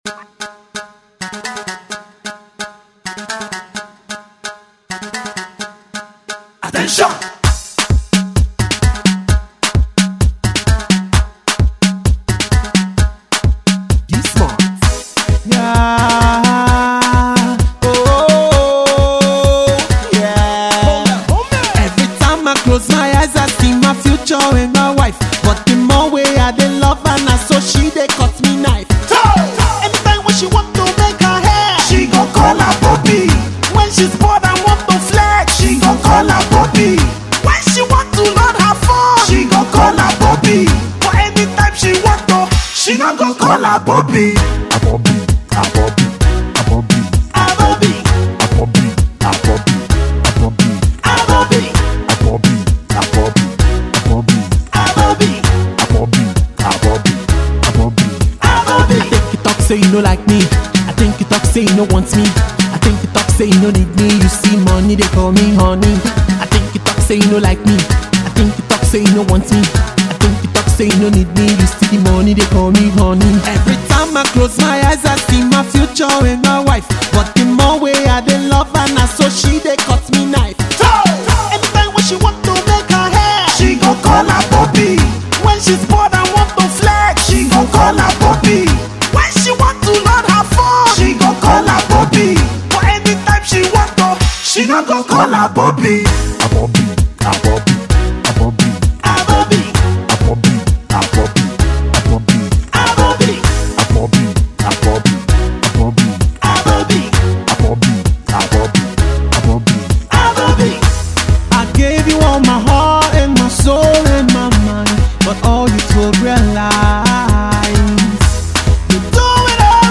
with his usual style of subtle messages with a lot of humour